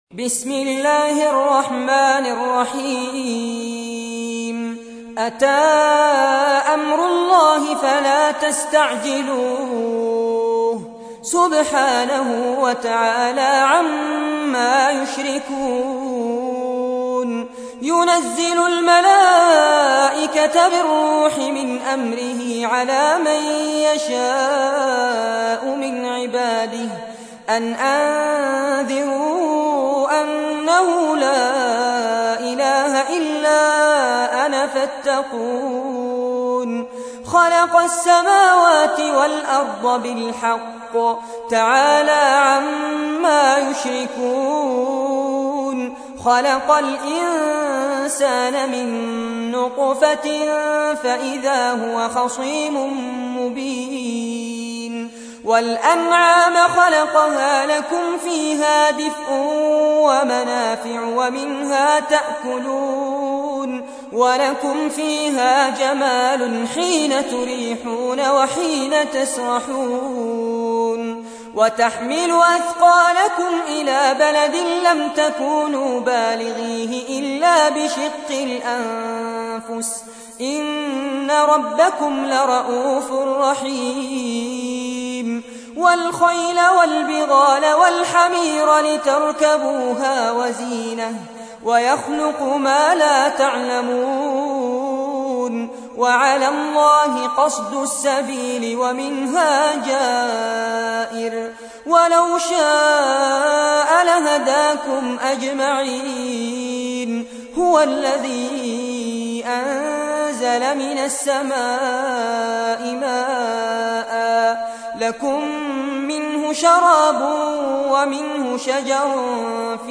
تحميل : 16. سورة النحل / القارئ فارس عباد / القرآن الكريم / موقع يا حسين